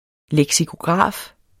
Udtale [ lεgsikoˈgʁɑˀf ]